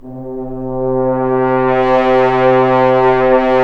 Index of /90_sSampleCDs/Roland L-CD702/VOL-2/BRS_Accent-Swell/BRS_FHns Swells